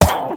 Minecraft Version Minecraft Version 1.21.4 Latest Release | Latest Snapshot 1.21.4 / assets / minecraft / sounds / entity / witch / hurt3.ogg Compare With Compare With Latest Release | Latest Snapshot
hurt3.ogg